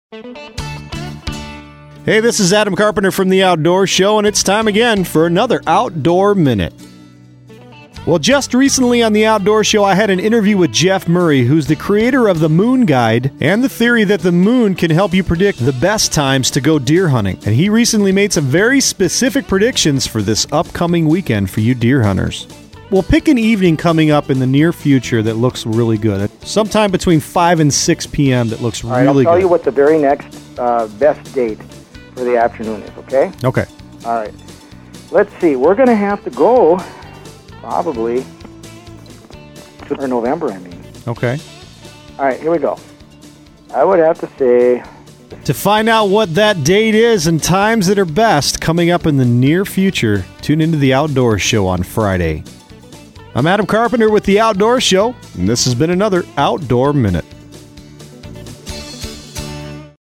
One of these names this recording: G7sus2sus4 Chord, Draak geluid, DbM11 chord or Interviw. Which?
Interviw